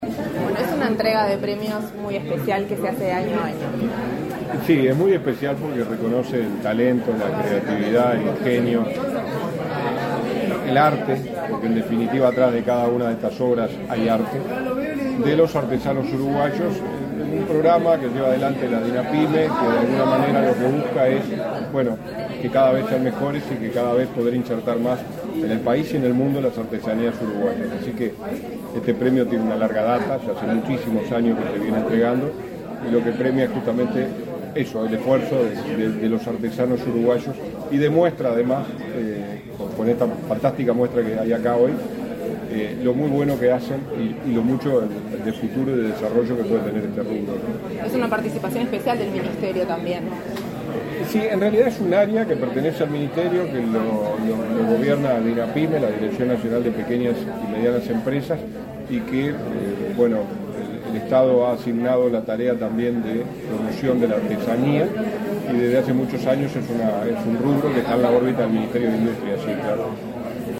Entrevista al ministro interino del MIEM, Walter Verri
Tras la ceremonia, el ministro interino de Industria, Energía y Minería (MIEM), Walter Verri, realizó declaraciones a Comunicación Presidencial.